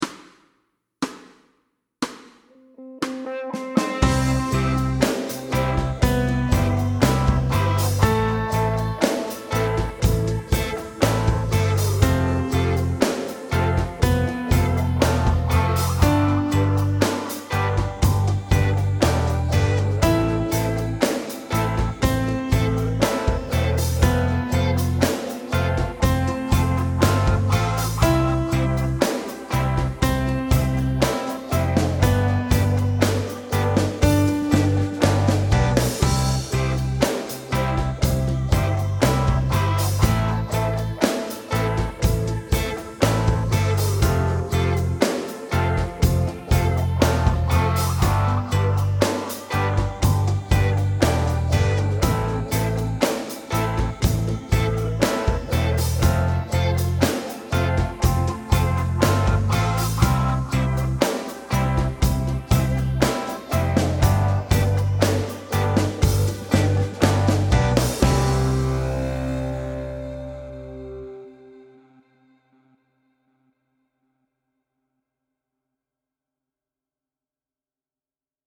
Slow C instr (demo)
Note values are whole notes, half notes and rests.